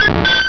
pokeemmo / sound / direct_sound_samples / cries / clefable.wav